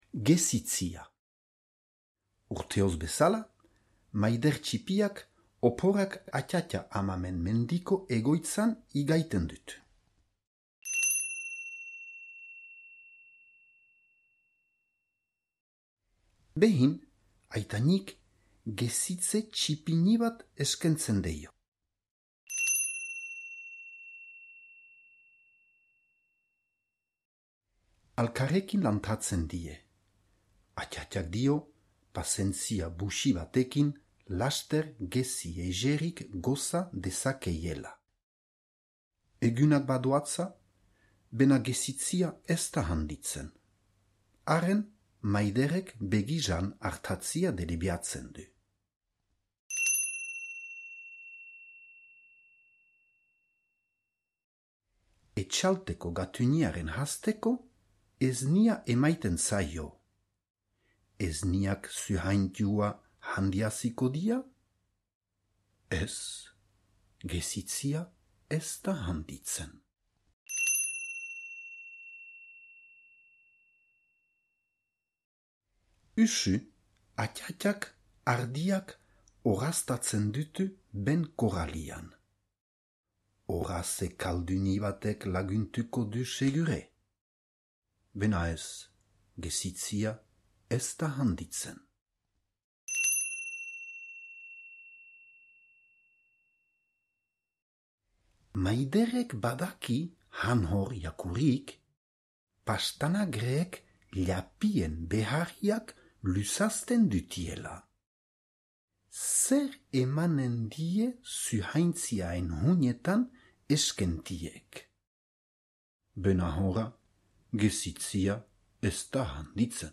Gerezitzea - zubereraz - ipuina entzungai